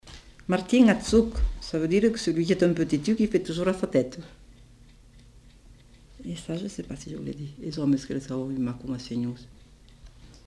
Lieu : Cathervielle
Genre : forme brève
Type de voix : voix de femme
Production du son : récité
Classification : locution populaire